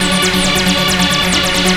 ACID LOOP00L.wav